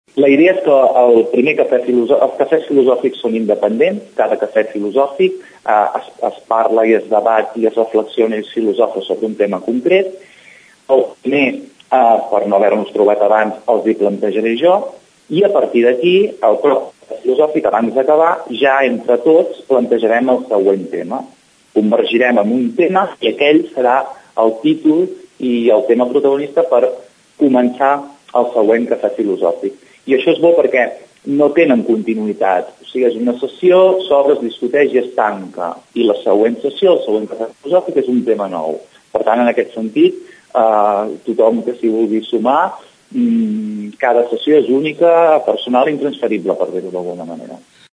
Ho explica en declaracions a Ràdio Tordera